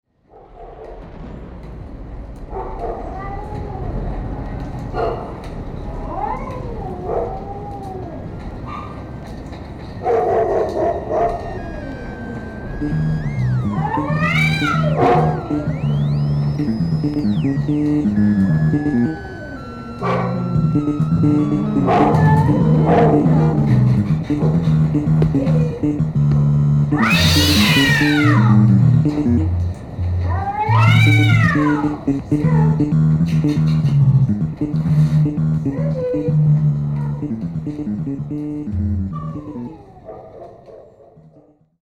キーワード：電子音　コラージュ　ミニマル